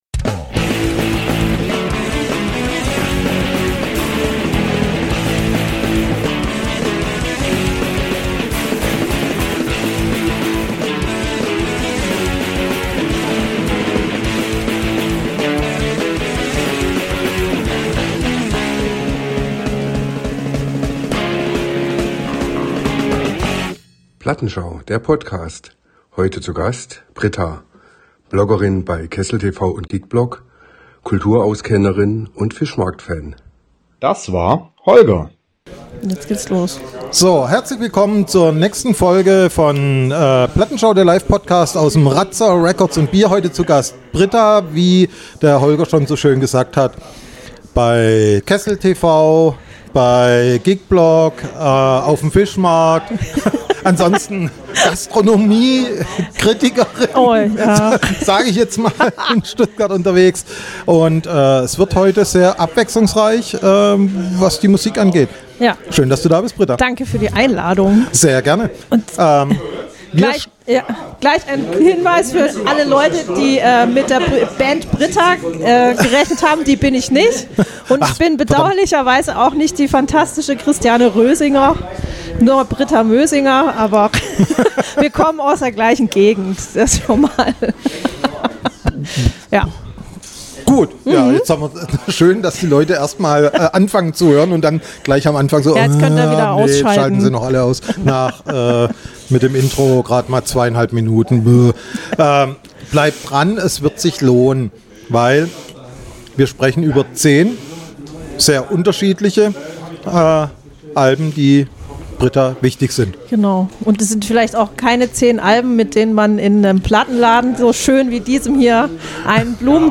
Dieses mal mit sovielen Zuschauer:innen wie noch nie. 1.